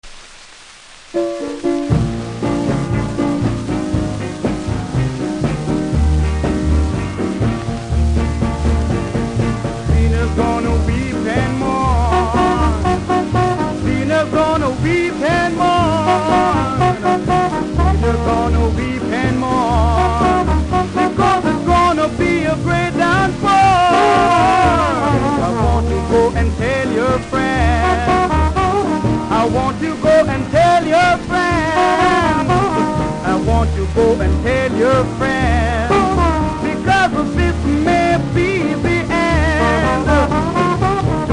この盤は全てプレス起因のヒスノイズがありますので試聴で確認下さい。
ラべル・ダメージがあり見た目悪いですがキズによるノイズは少ないと思います。